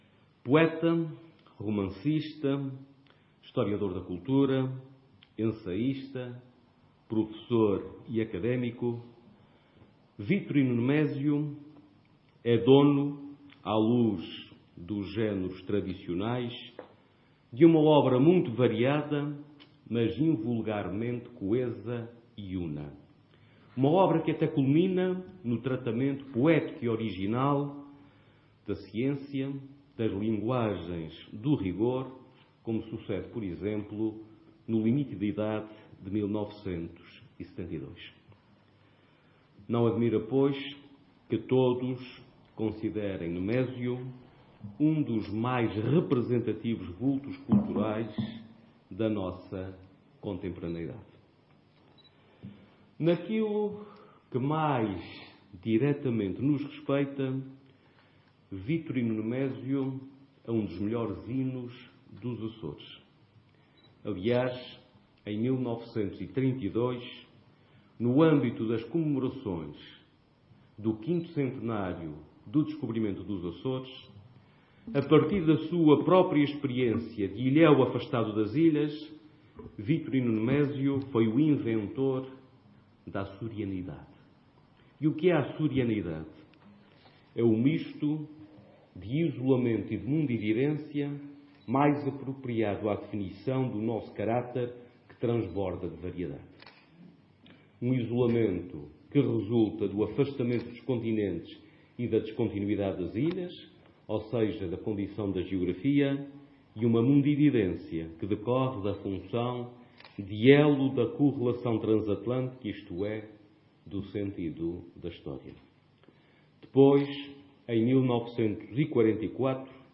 O Secretário Regional da Educação e Cultura afirmou, na Horta, que Vitorino Nemésio, autor do romance ‘Mau Tempo no Canal’,  “é um dos melhores hinos dos Açores”.
Avelino Meneses, que falava sexta-feira na sessão comemorativa do 70.º aniversário da primeira edição daquela obra literária, recordou que, em 1932,  no âmbito das comemorações do quinto centenário do descobrimento dos Açores, Vitorino Nemésio foi, “a partir da sua própria experiência de ilhéu afastado das ilhas, o inventor da Açorianidade”, que considerou ser “o misto de isolamento e mundividência mais apropriado à definição do nosso caráter que transborda de variedade”.